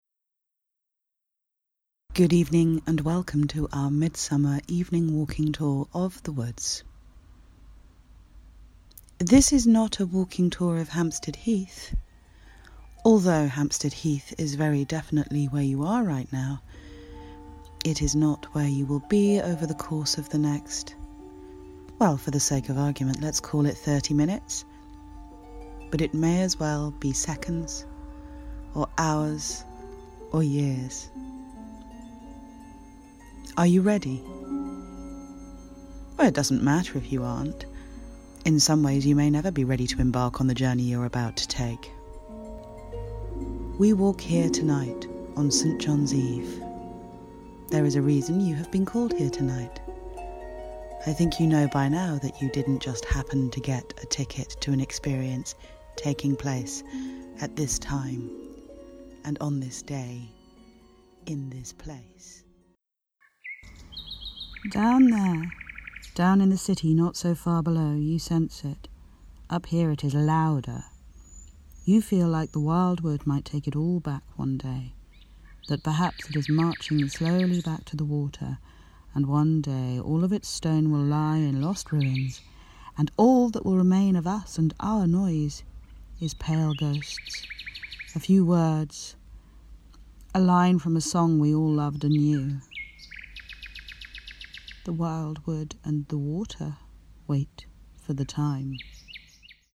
A sample of the story of The Wild Wood as interactive fiction.